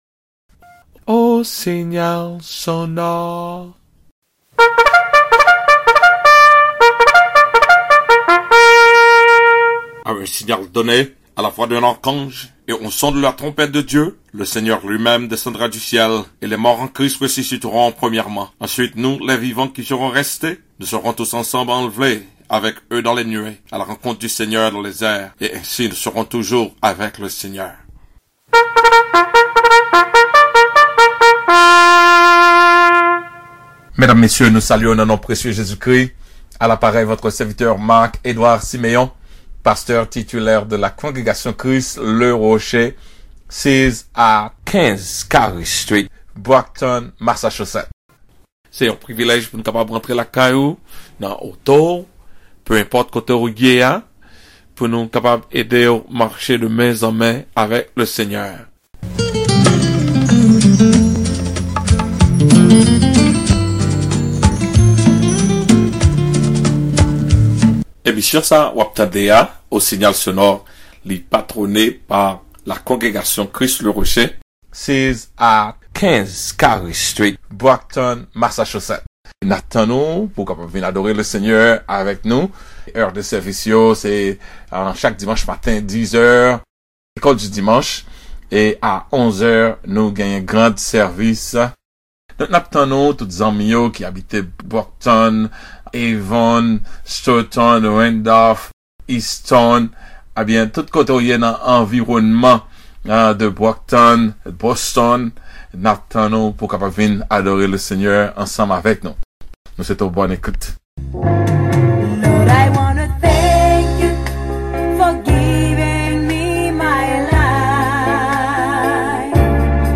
CLICK THIS TO DOWNLOAD (IL NE TROUVA QUE DES FEUILLES SERMON)